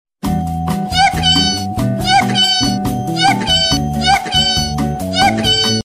Yipee sound effects free download